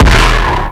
Drop [ Huncho ](1).wav